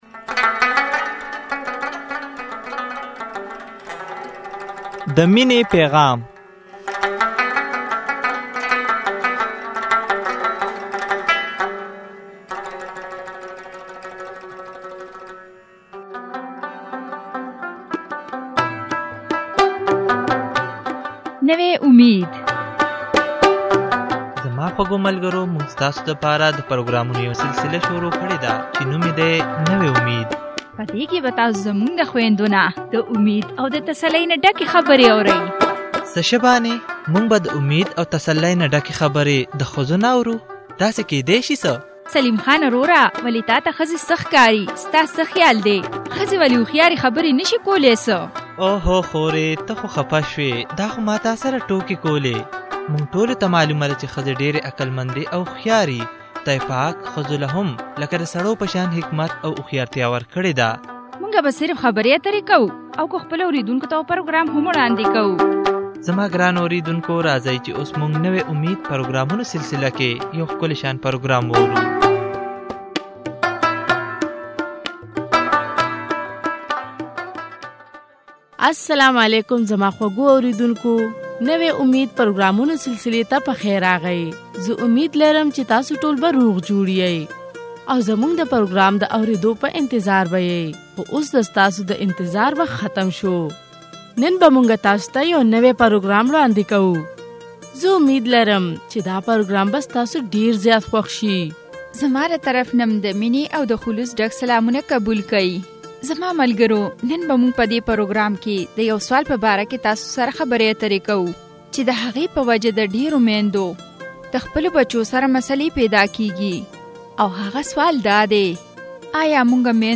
د کور جوړونکى په توګه د يوې ښځې کردار او په کور کښې د مور د کردار فائدې. يوه اُستاذه خبره کوى چې هغې د خپلو بچو د پالنې دپاره خپله نوکرى پرېښوده.